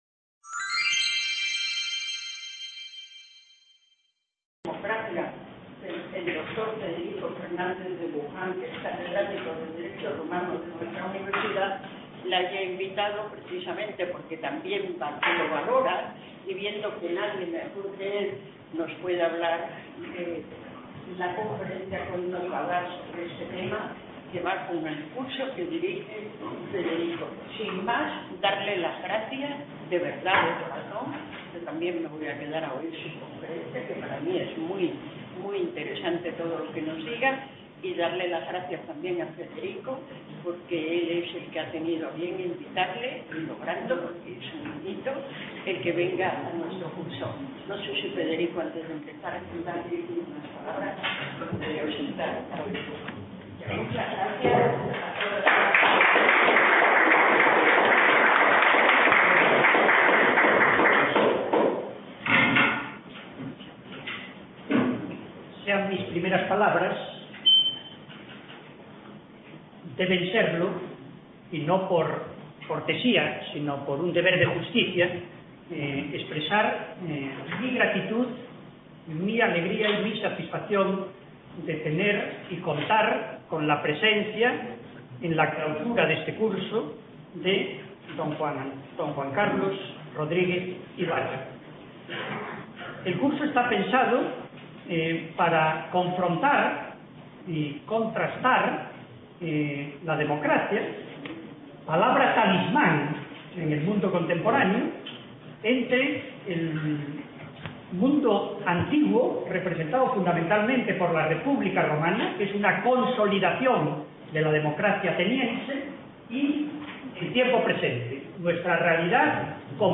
Ponencia llevada a cargo por Don Juan Carlos Rodriguez Ibarra .